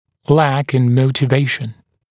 [læk ɪn ˌməutɪ’veɪʃn][лэк ин ˌмоути’вэйшн]иметь недостаточную мотивацию